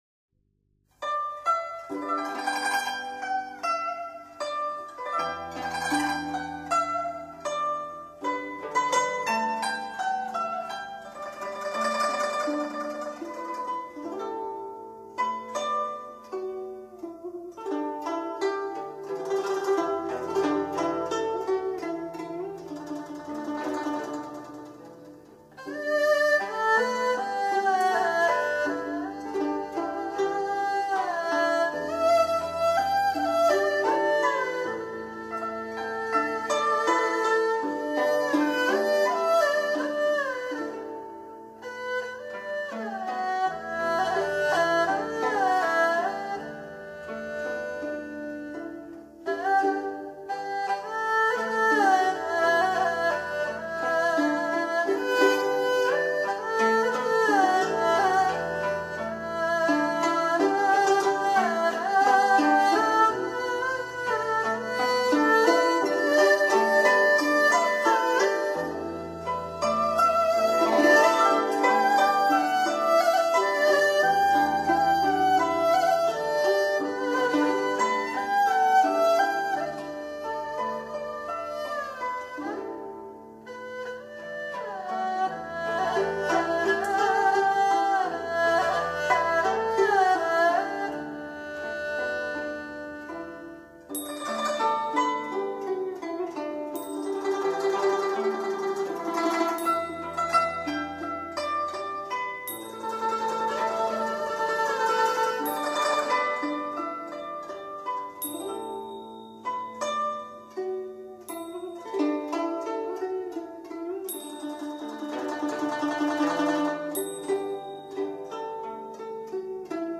01.刘三姐          (合奏)